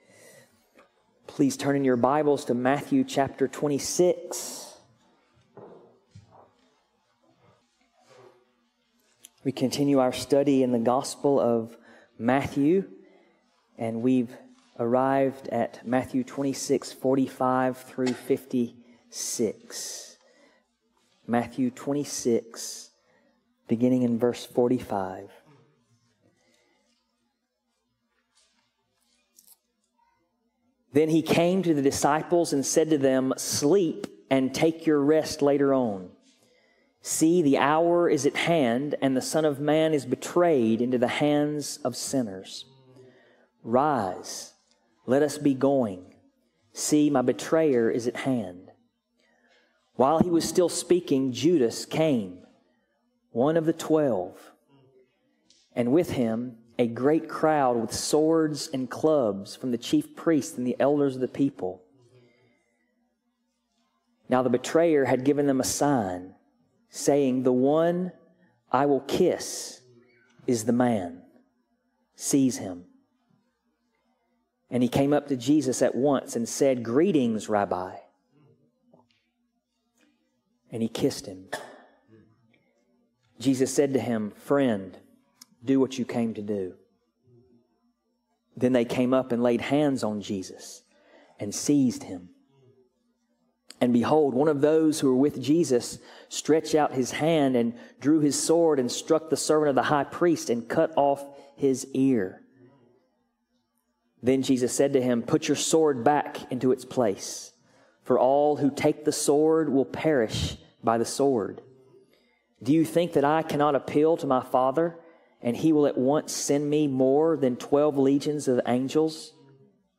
Passage: MatMatthew 26:45-56thew 26:45-56 Service Type: Sunday Morning